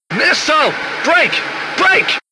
Misil5.ogg